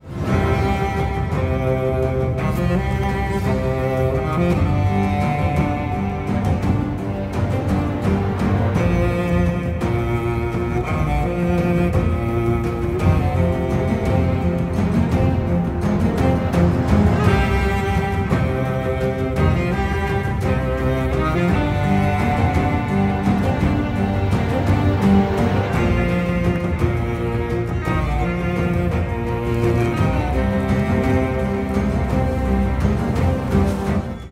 саундтреки
без слов
инструментальные
эпичные